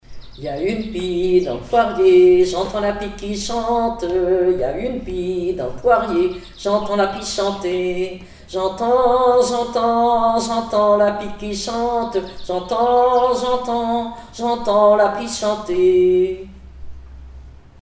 Couplets à danser
enfantine : berceuse
Comptines et formulettes enfantines
Pièce musicale inédite